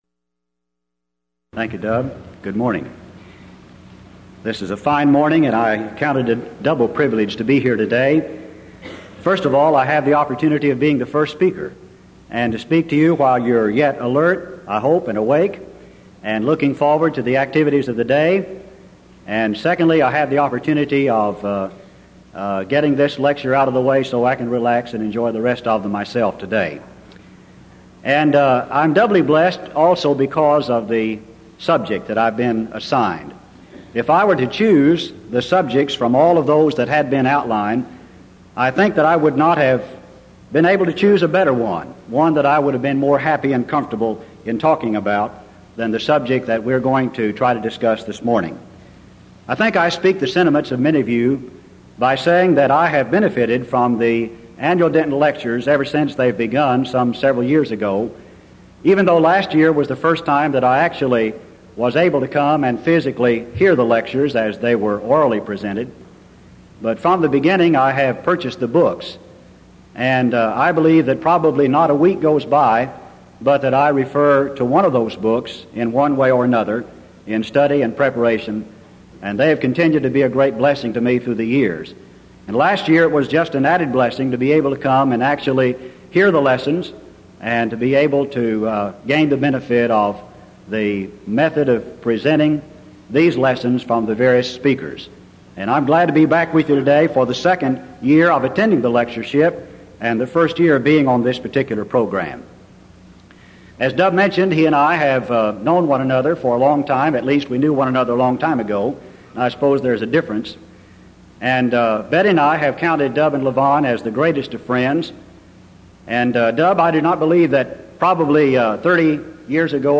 Event: 1989 Denton Lectures
If you would like to order audio or video copies of this lecture, please contact our office and reference asset: 1989Denton14